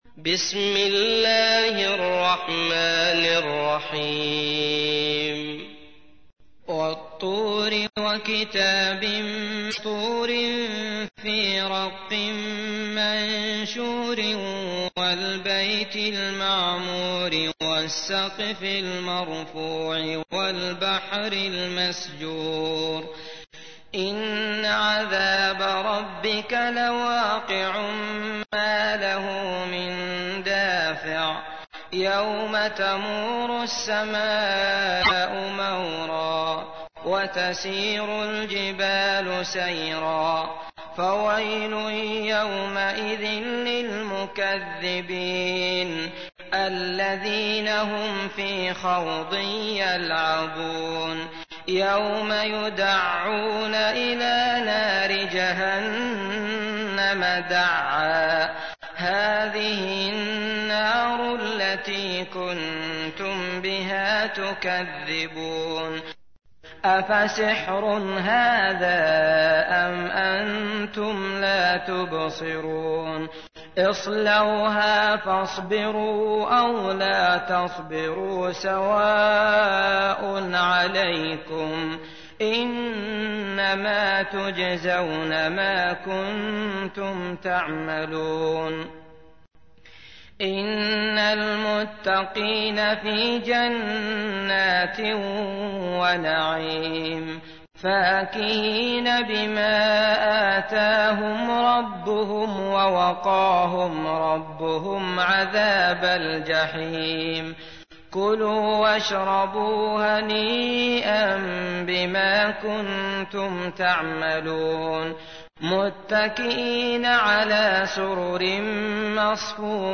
تحميل : 52. سورة الطور / القارئ عبد الله المطرود / القرآن الكريم / موقع يا حسين